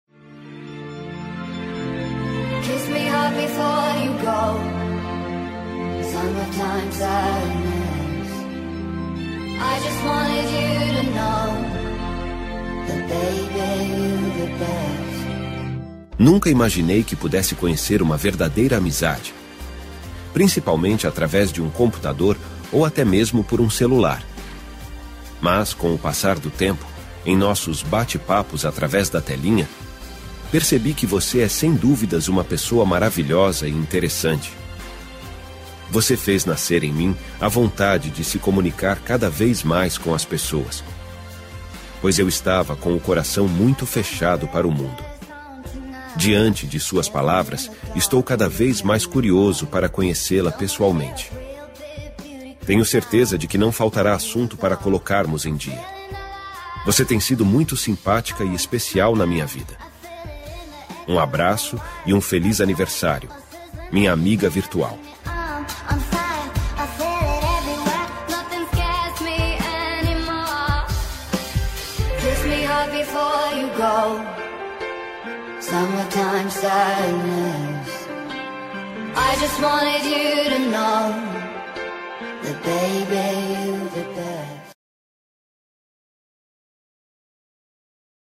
Aniversário Virtual Distante – Voz Masculina – Cód: 8890
aniv-virtual-masc-8890.m4a